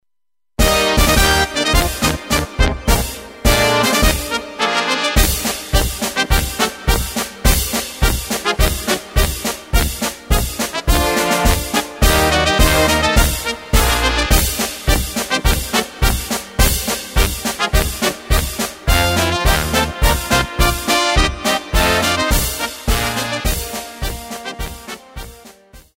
Takt:          2/4
Tempo:         105.00
Tonart:            Eb
Marsch Blasmusik instrumental!
Playback mp3 Demo